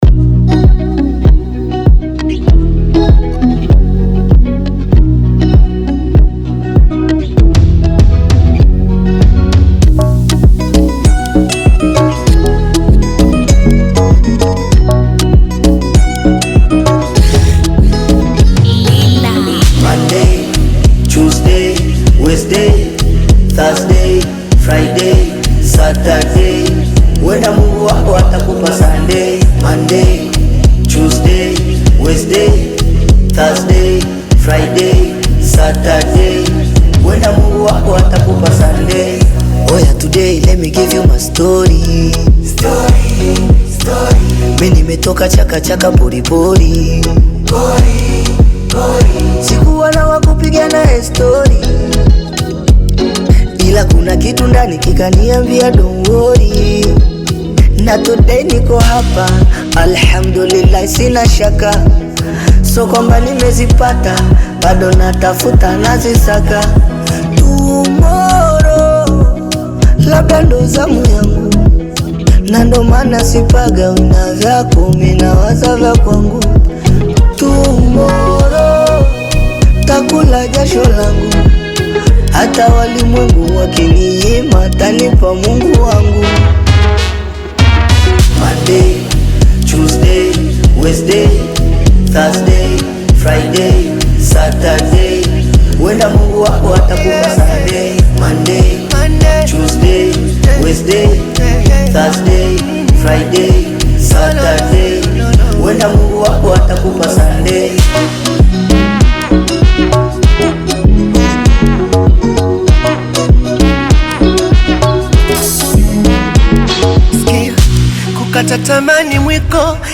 uplifting Afro-Pop/Bongo Flava single